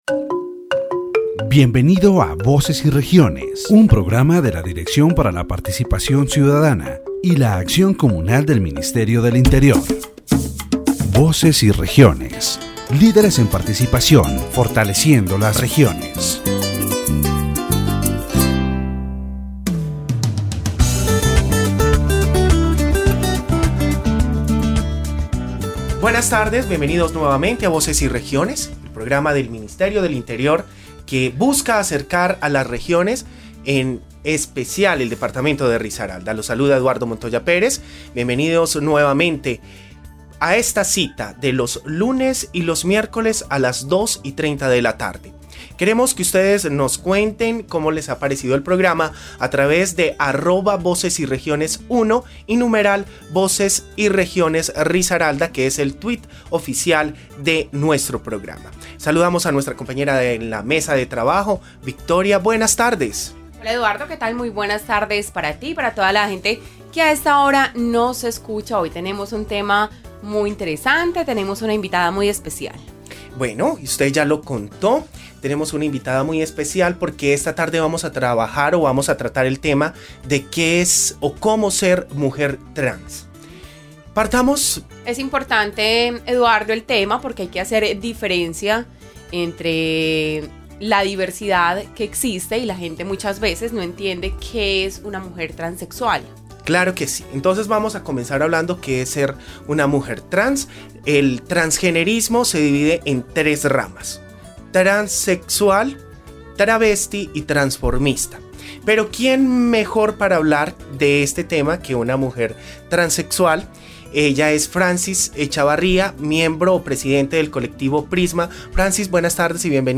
The radio program "Voices and Regions" of the Directorate for Citizen Participation and Community Action of the Ministry of the Interior addresses the issue of being a trans woman.